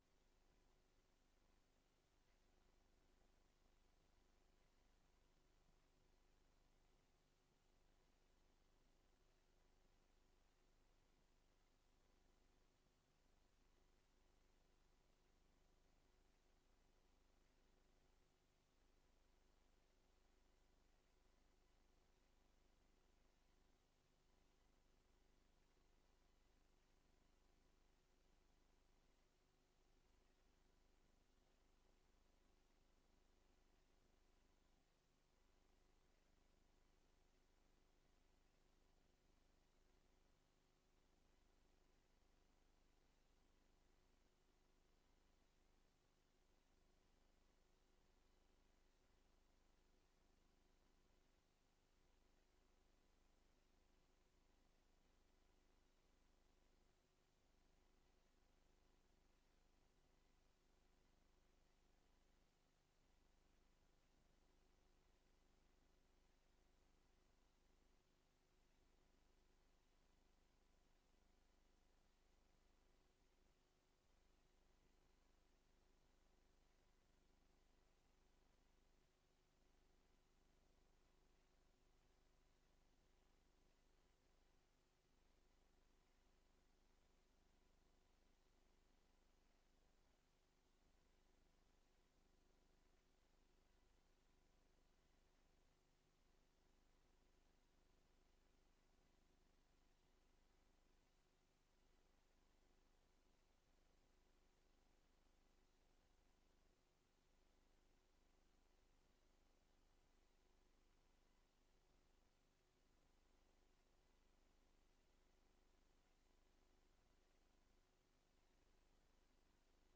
Raadsvergadering 30 maart 2022 20:00:00, Gemeente Leusden
Locatie: Raadzaal